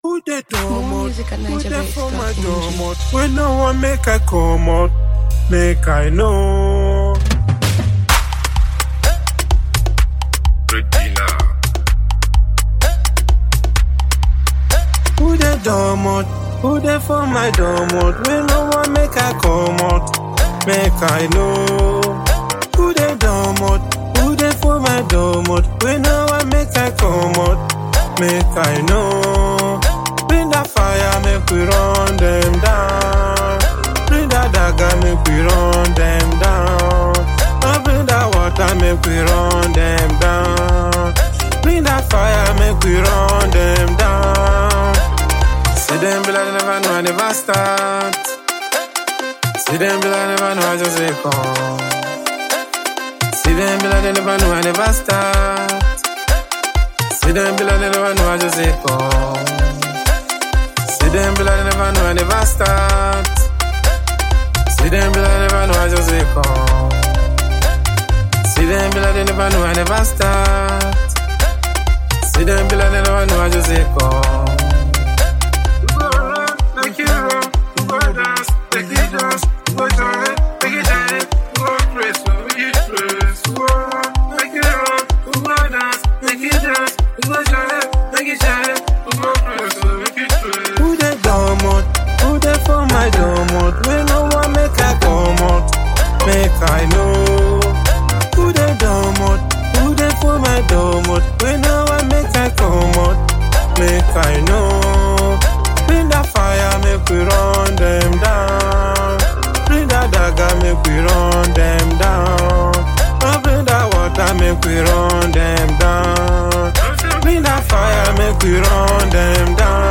high-energy anthem